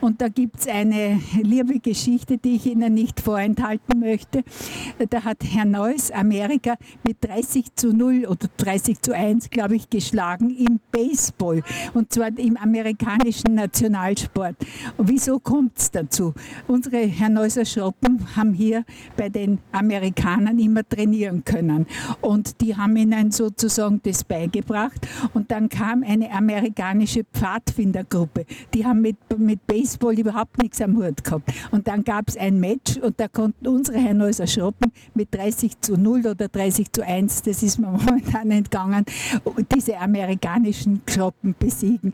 gehcafe-hernals-baseball-postsportplatz.mp3